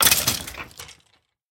sounds / mob / skeleton / death.mp3
death.mp3